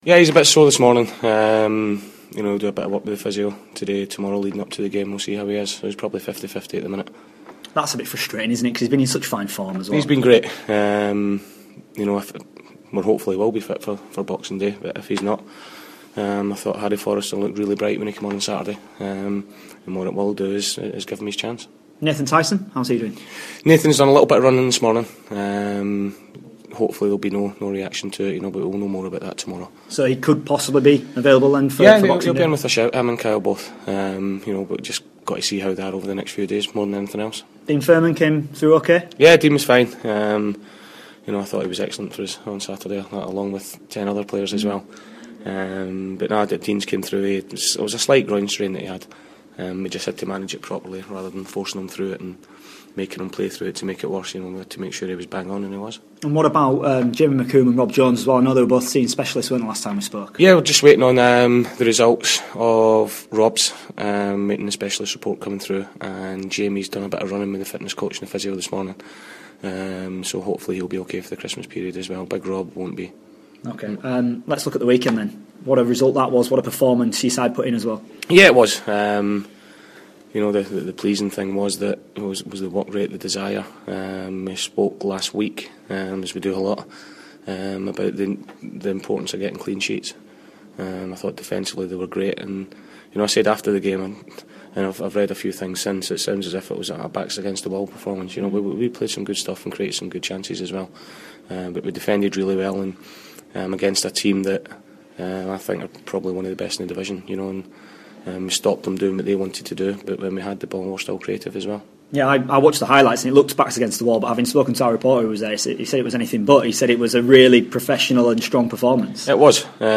INTERVIEW: Doncaster Rovers manager Paul Dickov ahead of their boxing day game with Coventry.